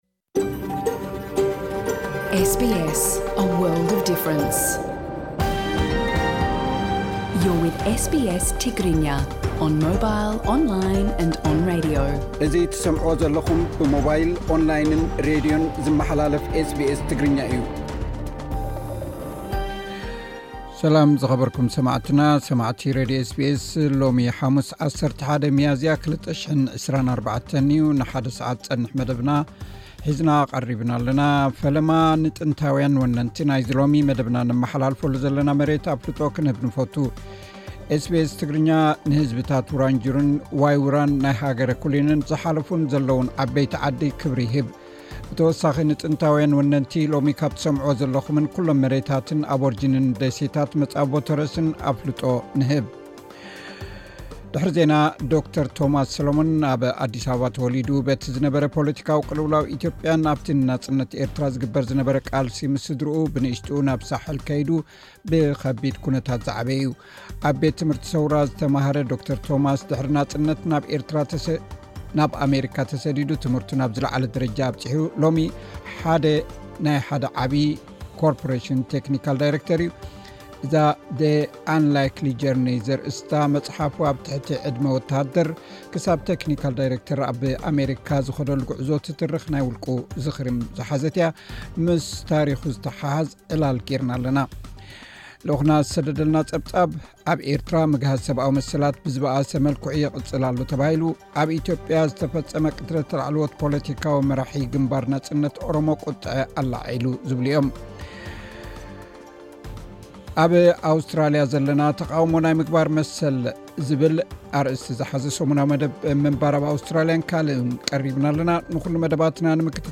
ምስ ታሪኹ ዝተሓሓዘ ዕላል ገይርና ኣለና።